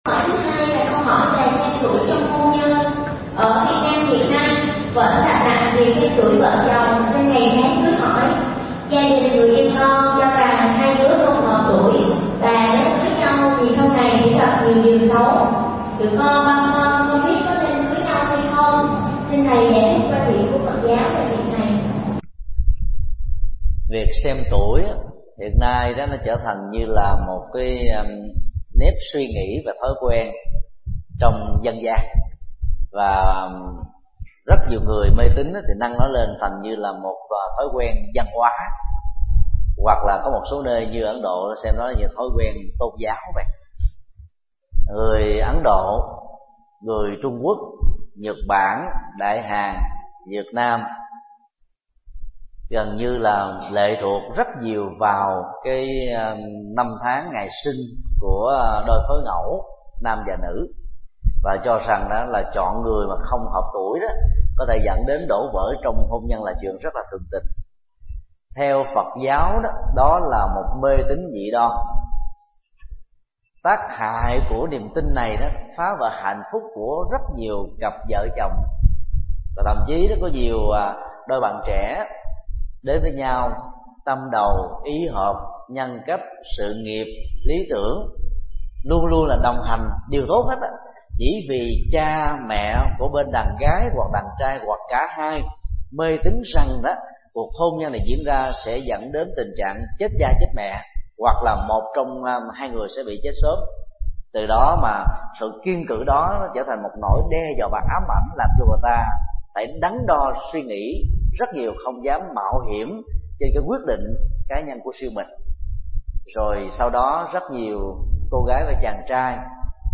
Vấn đáp: Mê tín trong việc xem tuổi, ngày tổ chức hôn lễ – Thích Nhật Từ